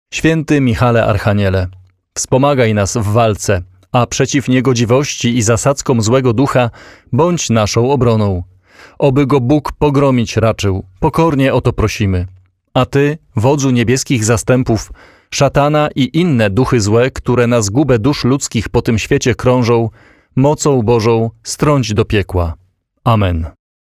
U progu wakacji weszliśmy do studia Radia eM, żeby nagrać wybrane przez Was w sondzie modlitwy.